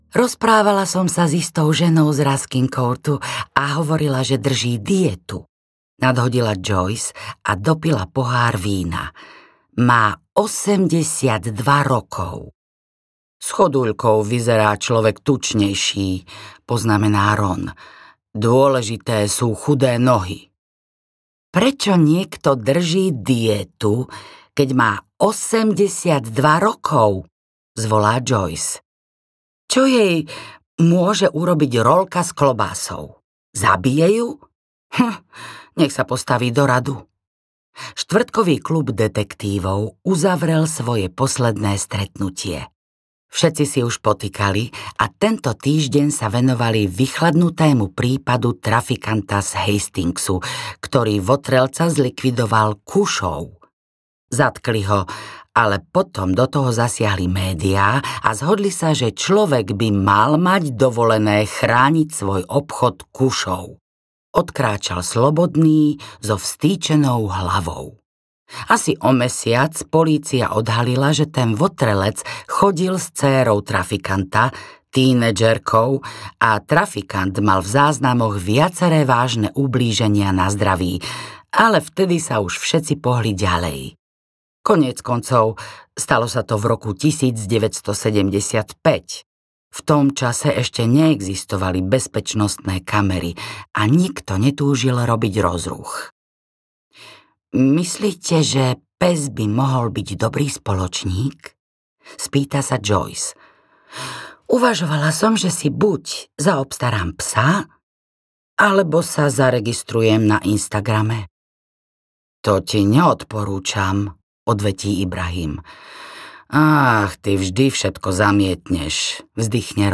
Dva razy mŕtvy audiokniha
Ukázka z knihy